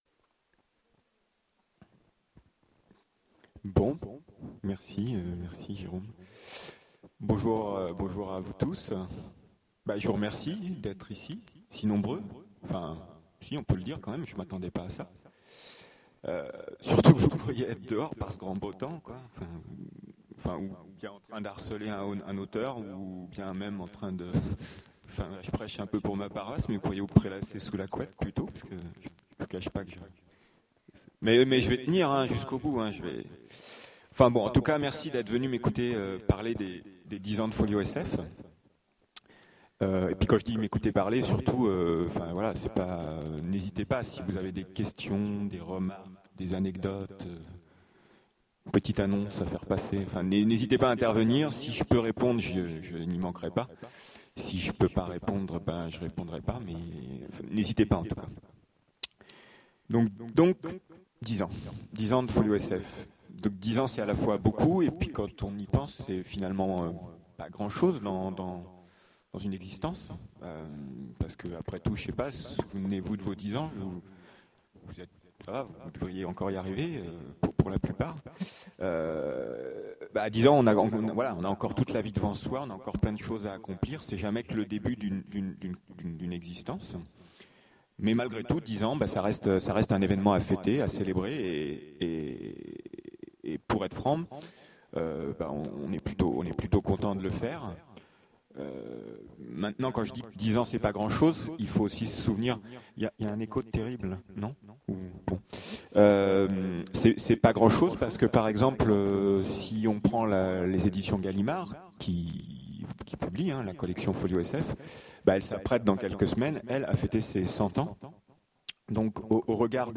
Voici l'enregistrement de la conférence "Les 10 ans de Folio SF" lors des 7ème rencontres de l'Imaginaire de Sèvres.